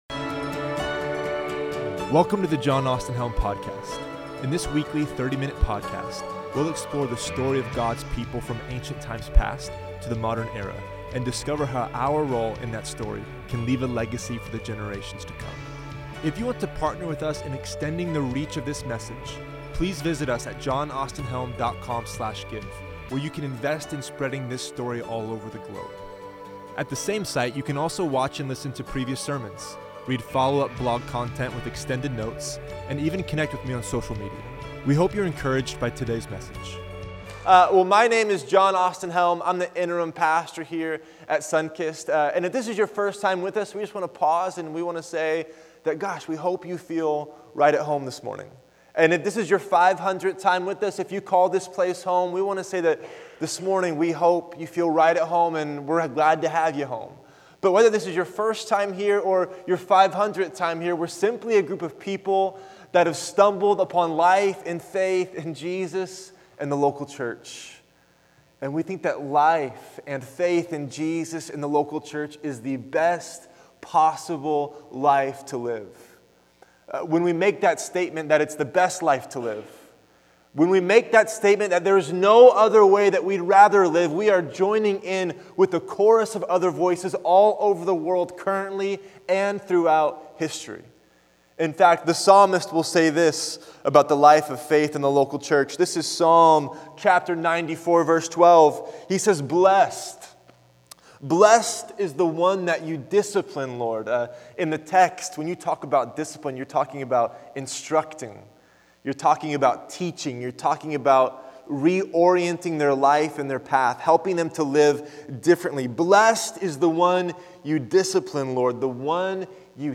Recorded live at Sunkist Church on January 15, 2017